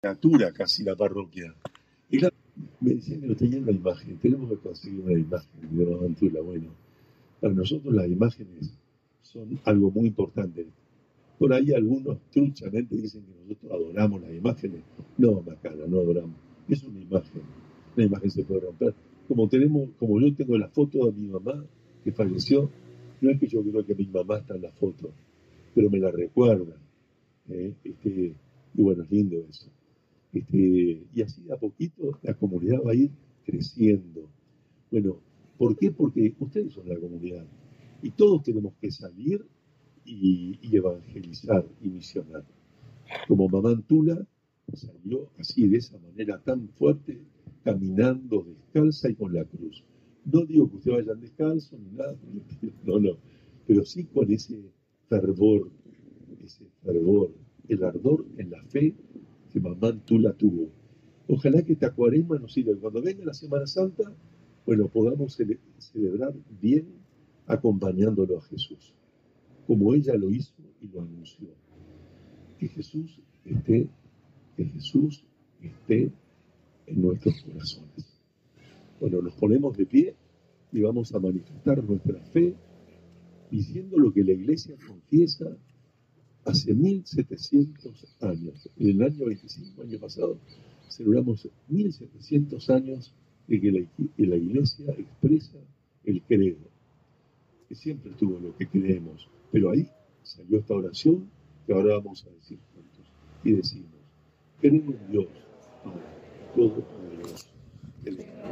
El obispo Martínez presidió la misa por el Día de Santa Mama Antula en Itaembé Miní: